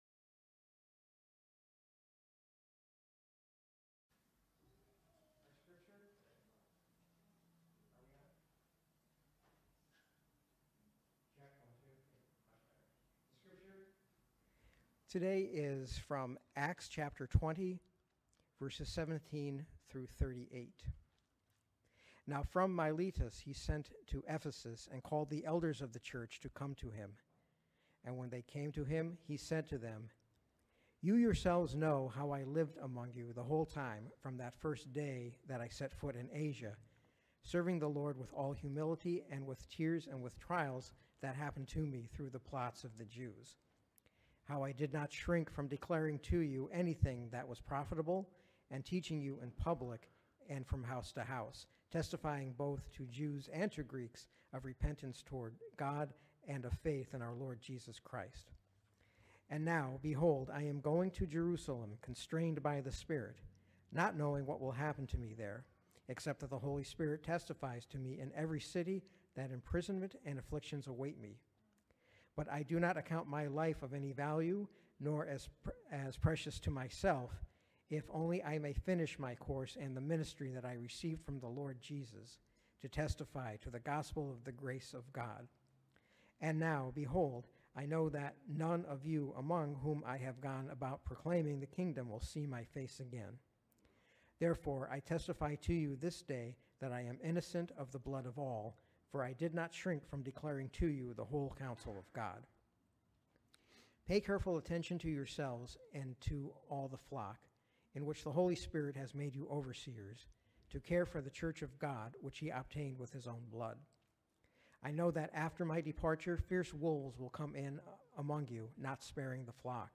Grace Sermons | Grace Evangelical Free Church